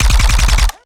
GUNAuto_RPU1 B Burst_01_SFRMS_SCIWPNS.wav